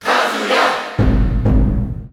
File:Kazuya Cheer Dutch SSBU.ogg
Kazuya_Cheer_Dutch_SSBU.ogg.mp3